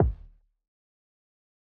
Rich City Kick.wav